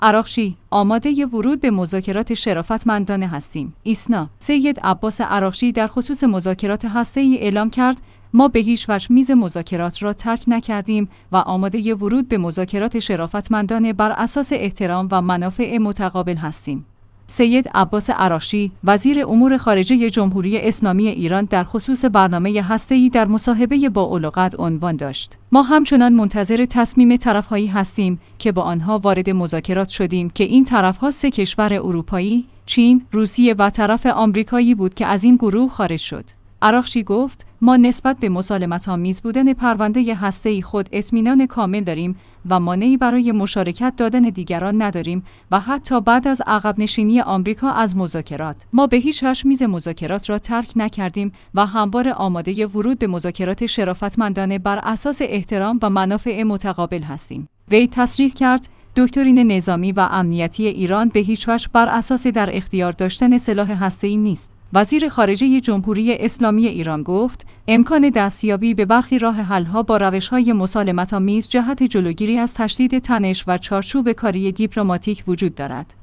بازار سید عباس عراقچی، وزیر امور خارجه جمهوری اسلامی ایران درخصوص برنامه هسته‌ای در مصاحبه با الغد عنوان داشت: ما همچنان منتظر ت